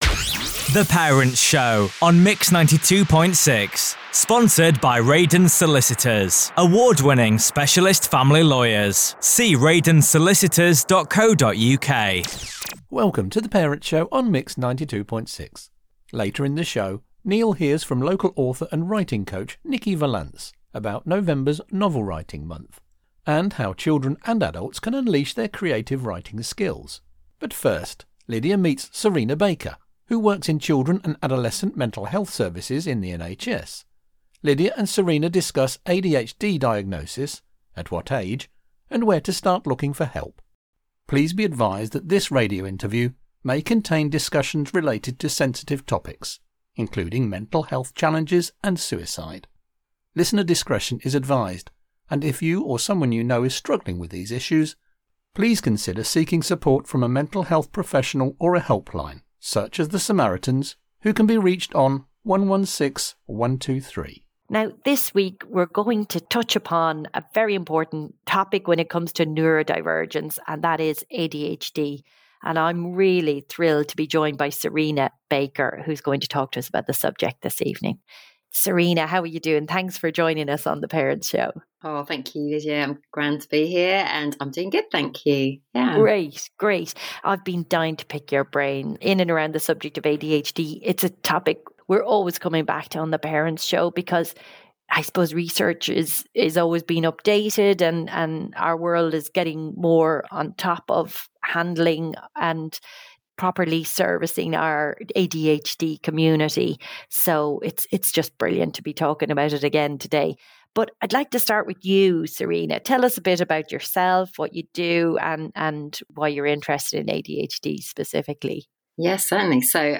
in-depth interview